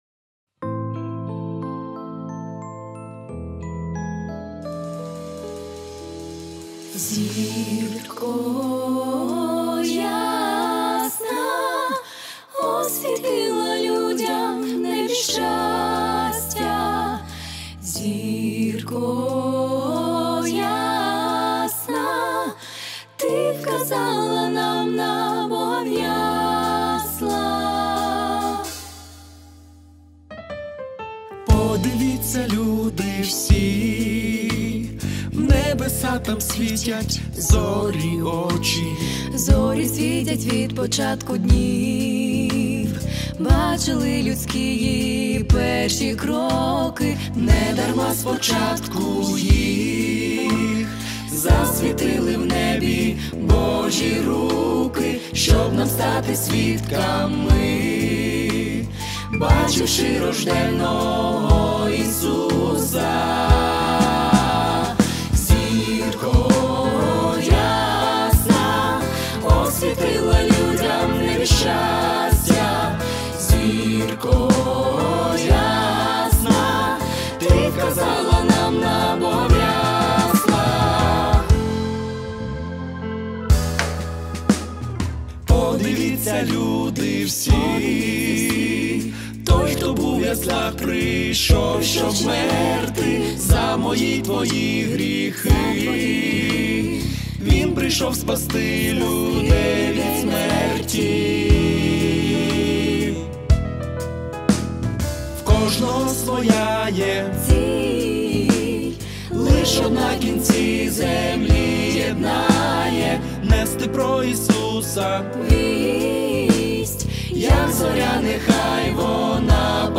900 просмотров 578 прослушиваний 64 скачивания BPM: 120